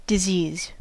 Ääntäminen
IPA : /dɪ.ˈziːz/